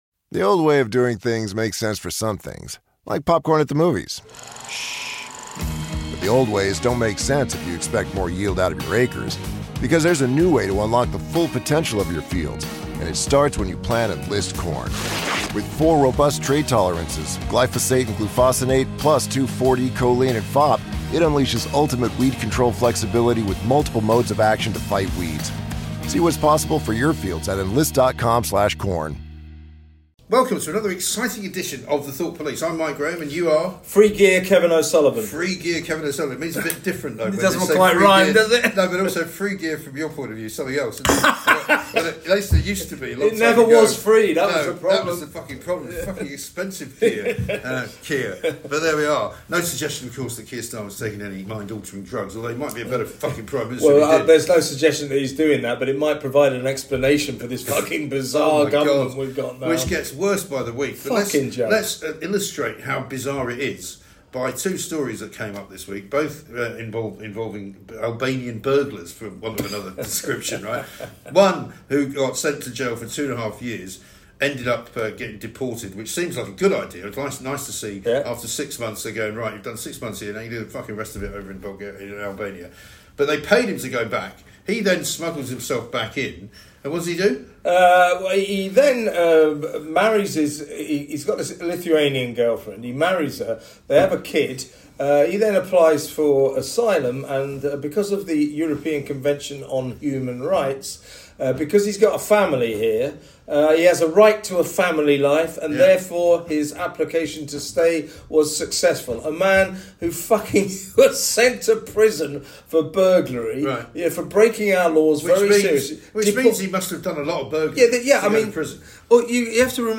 The lads are back with another rage filled deep dive into the past weeks top stories, and the very worst...